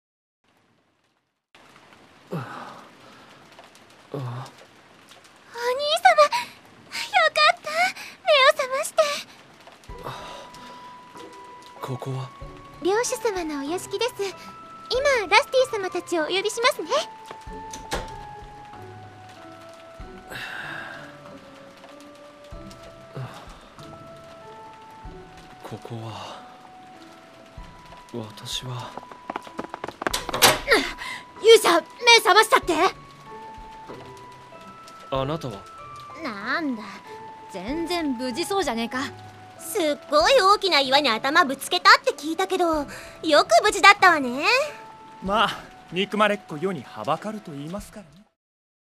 ドラマCD